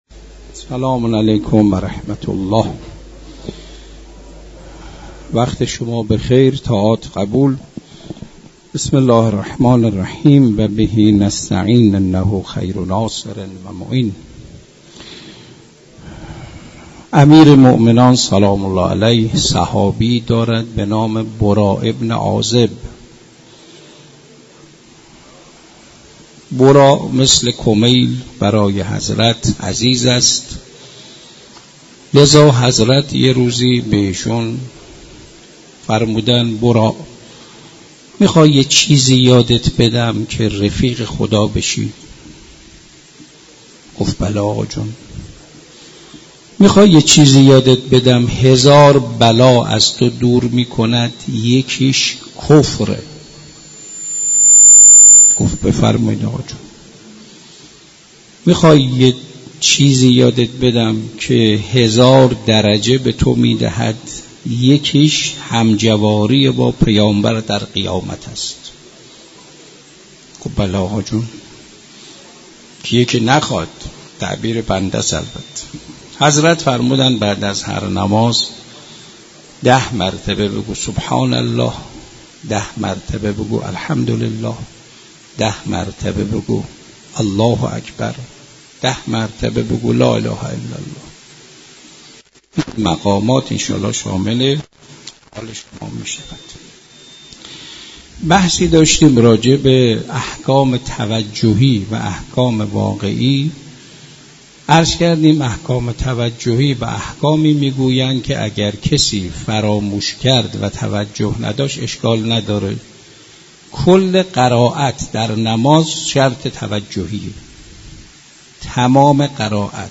در مسجد دانشگاه